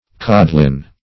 Codlin \Cod"lin\, Codling \Cod"ling\, n. [Cf. AS. cod[ae]ppel a